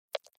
دانلود آهنگ کلیک 28 از افکت صوتی اشیاء
جلوه های صوتی
دانلود صدای کلیک 28 از ساعد نیوز با لینک مستقیم و کیفیت بالا